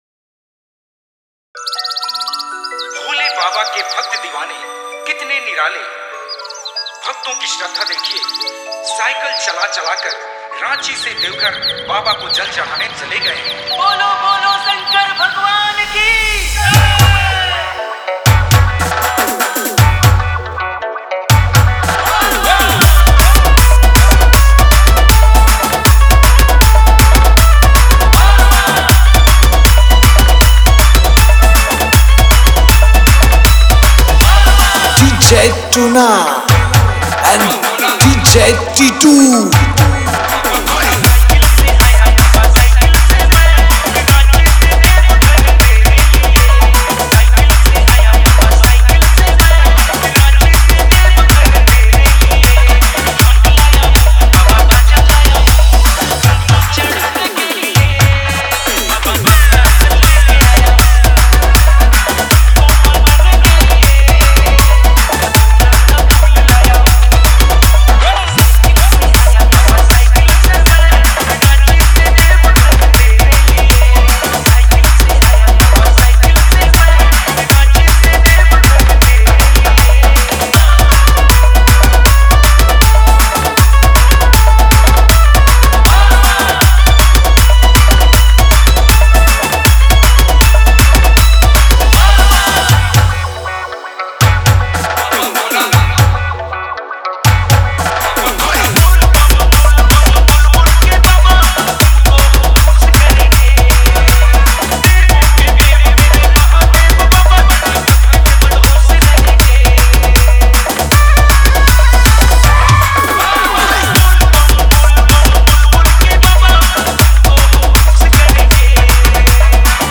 Bolbum Special Dj Song Songs Download